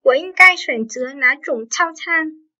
Wǒ yīnggāi xuǎnzé nǎ yīzhǒng tàocān?
Ủa inh cai xoẻn chứa nả y trủng thao chan?